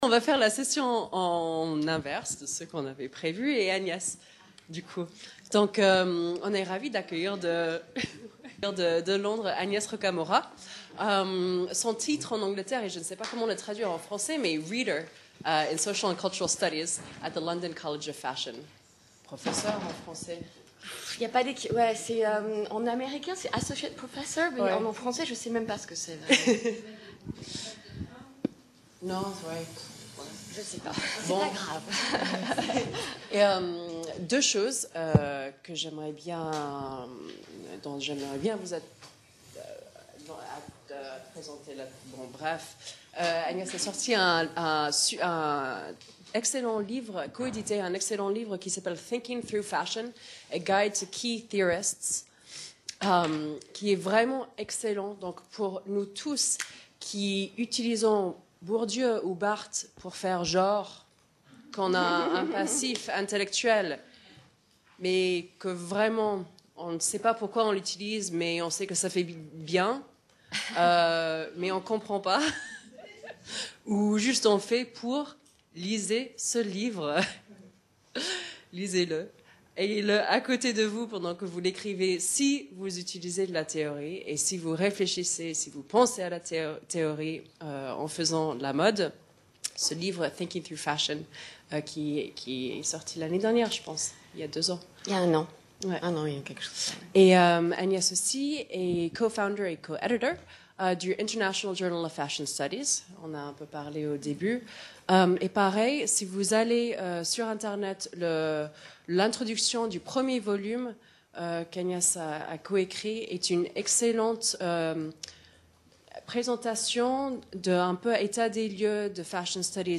SEMINAIRE DU 29 SEPTEMBRE 2017